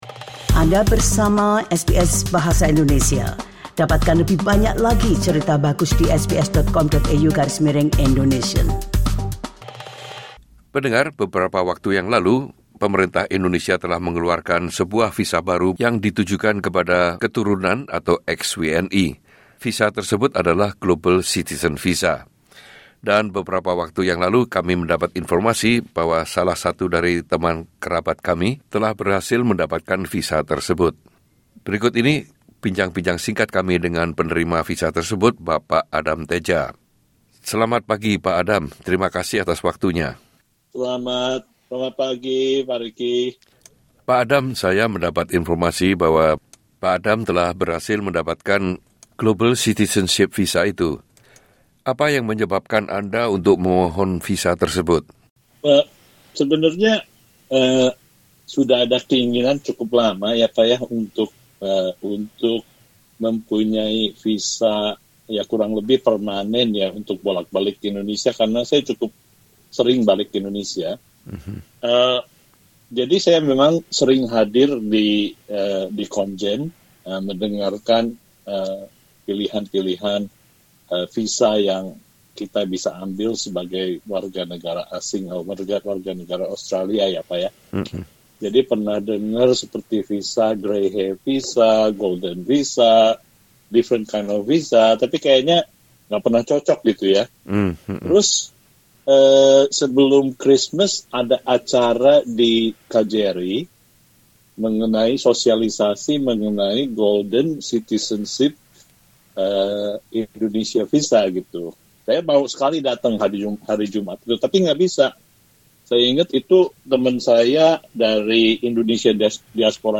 yang membagikan pengalamannya dalam sebuah wawancara singkat.